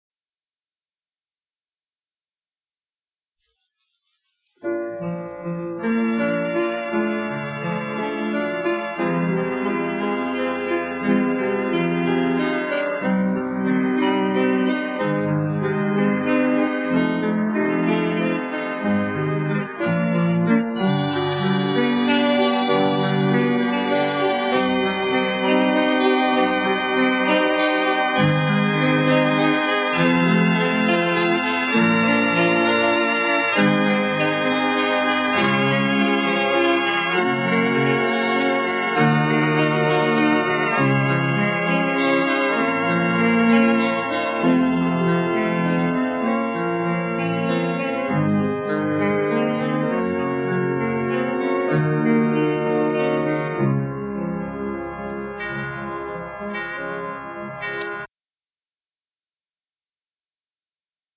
(interlude)
INSTRUMENTALISTS
ORGAN, SYNTHESIZER
GUITAR, SYNTHESIZER
BASS GUITAR
DRUMS